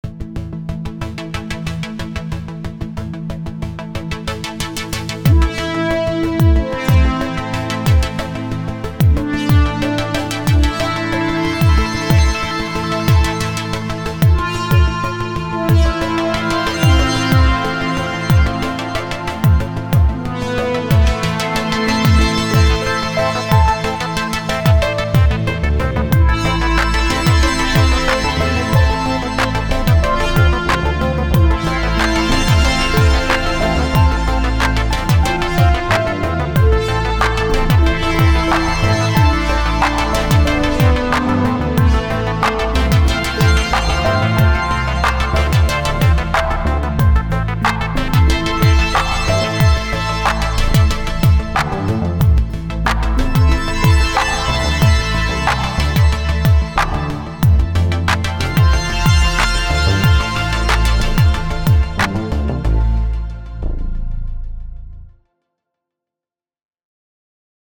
synth-heavy epics
blends analog vibe with modern power
Hope you enjoy these cinematic and epic sounds!